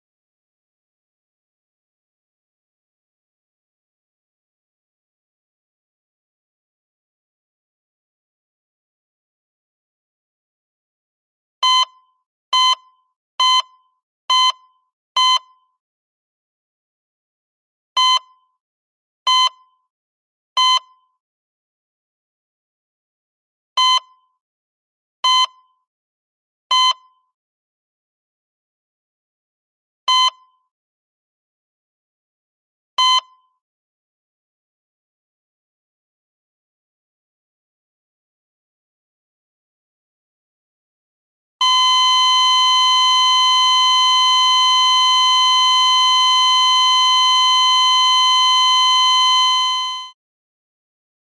Real Heartbeat Flatline Failure